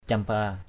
/cam-pa:/ 1.